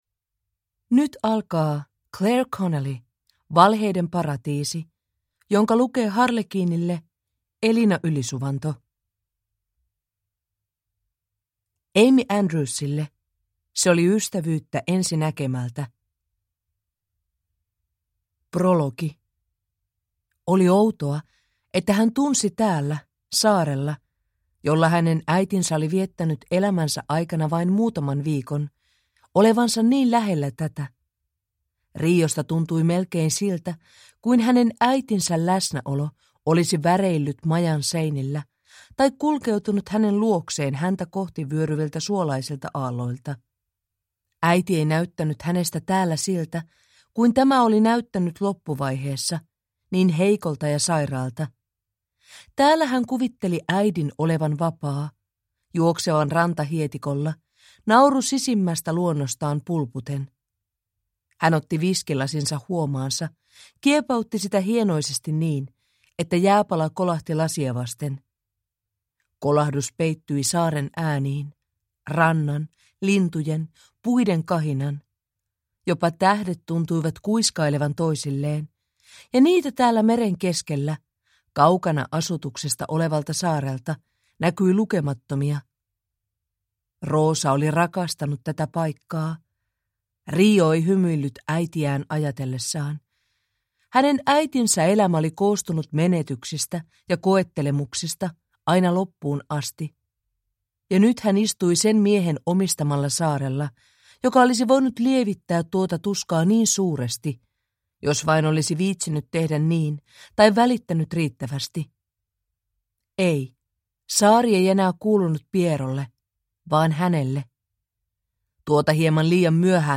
Valheiden paratiisi (ljudbok) av Clare Connelly